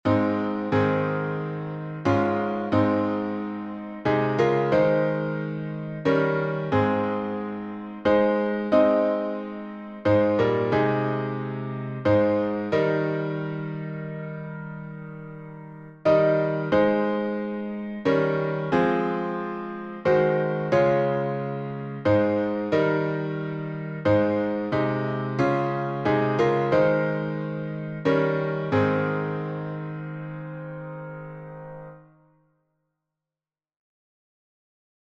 Alas! and Did My Savior Bleed — A flat major with five stanzas — Martyrdom.
Key signature: A flat major (4 flats) Time signature: 3/4